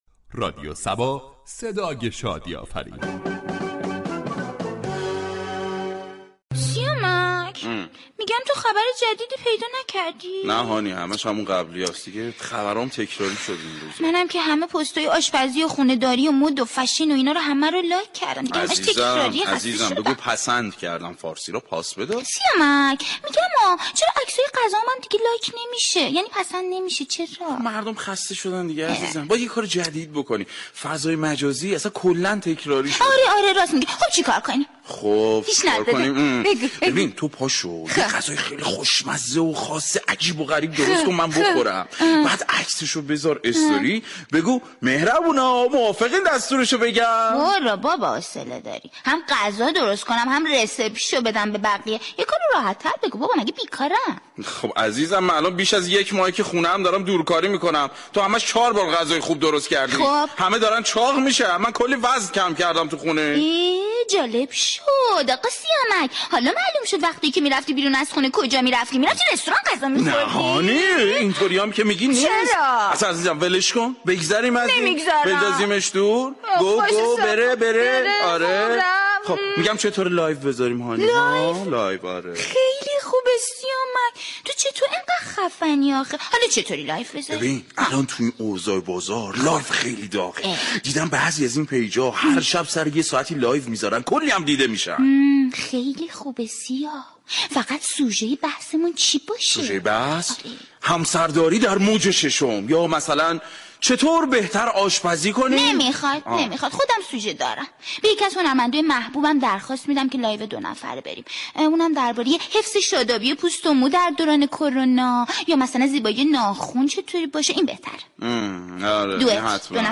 شهر فرنگ در بخش نمایشی با بیان طنز به موضوع " تب دیده شدن در فضای مجازی"پرداخته است ،در ادامه شنونده این بخش باشید.